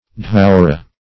Search Result for " dhourra" : The Collaborative International Dictionary of English v.0.48: Dhoorra \Dhoor"ra\, Dhourra \Dhour"ra\, or Dhurra \Dhur"ra\, n. Indian millet.